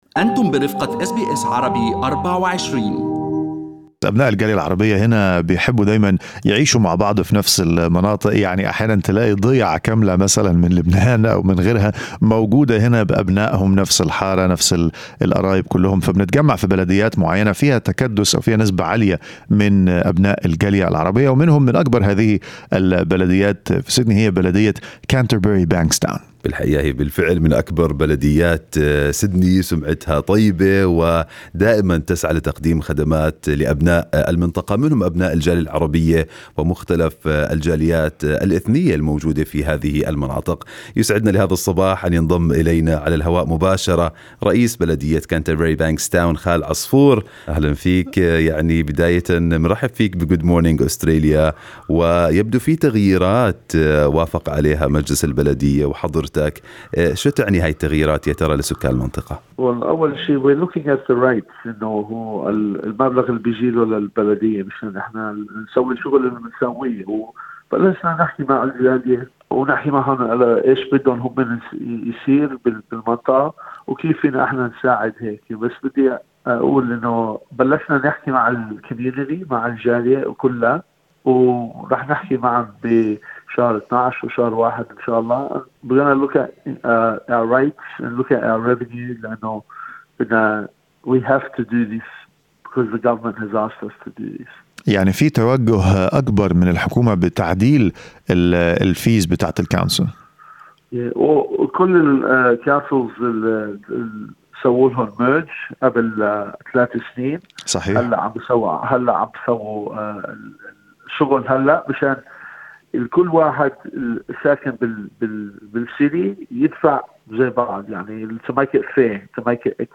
لقاء مع رئيس بلدية Canterbury-Bankstown كارل عصفور، الذي أوضح الأسباب وراء اعادة النظر في رسوم البلدية.